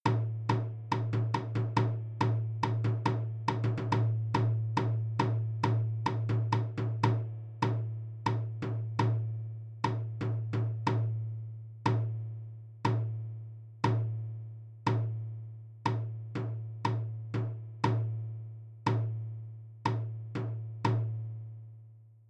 Now practice going from a fast section to a slower one.
Fast to Slow Transition
Transition-Practice-Fast-to-Slow.mp3